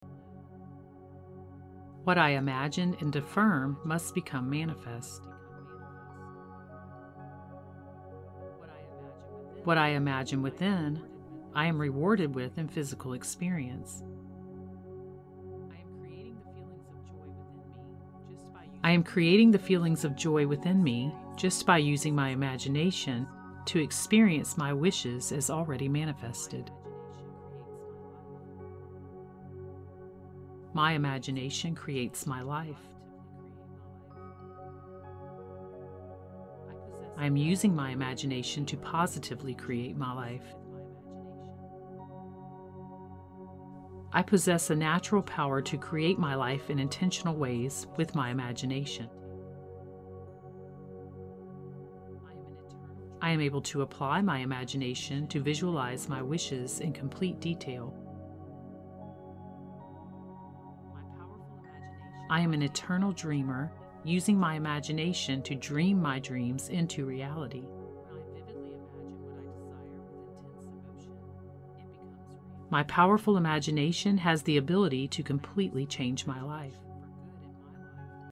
Each affirmation is delivered with dual-induction technology (switching from ear-to-ear and back to center) to strongly permeate and impress your subconscious in a way that reconditions your mind so you can change what you attract and manifest. The meditation music has been tuned to 528 Hz to assist you in programming your mind to manifest more quickly and promote a confident state.
I-Am-Pure-Imagination-Affirmations-Audio-Sample-2.mp3